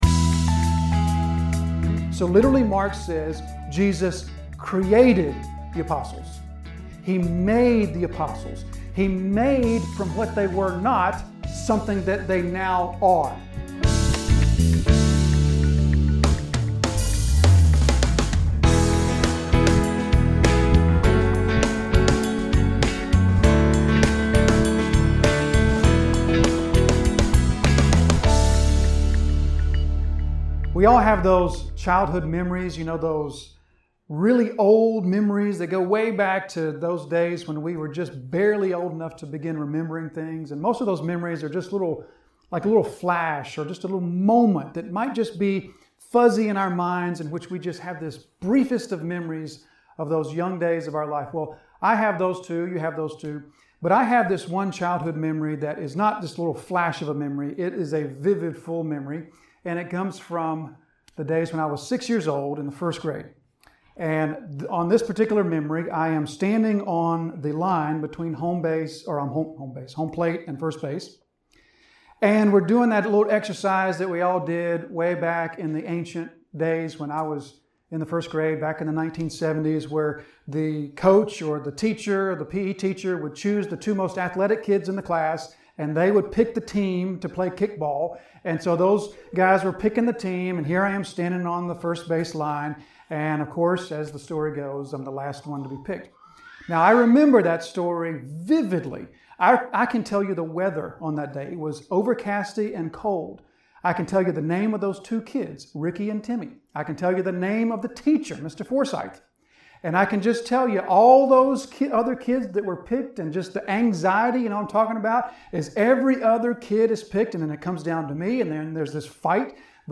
An expository sermon delivered at Disciples Fellowship Church, Jonesville, NC.